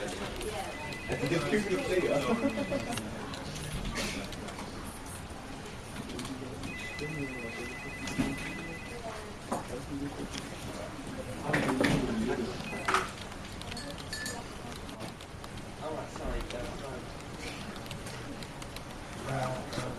Free Sci-Fi sound effect: Spaceship Ambient.
Spaceship Ambient
# spaceship # ambient # hum # scifi About this sound Spaceship Ambient is a free sci-fi sound effect available for download in MP3 format.
413_spaceship_ambient.mp3